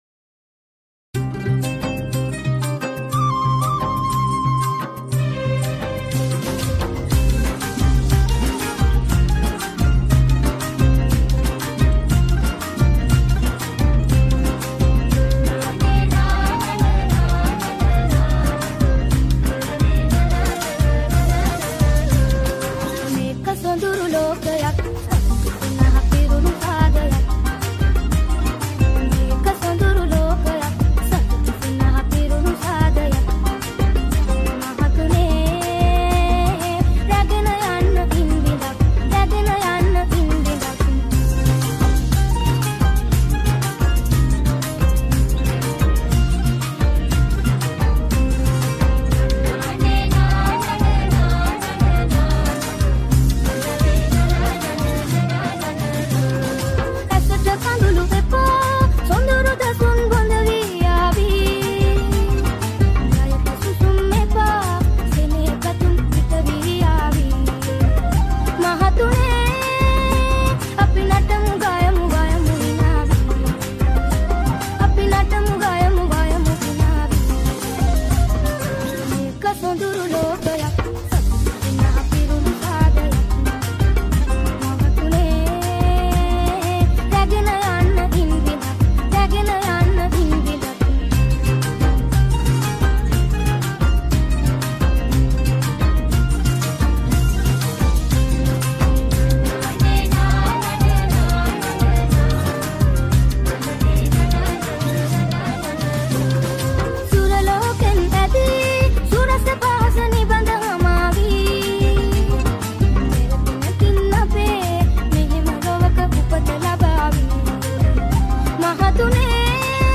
Kawadi Dance Remix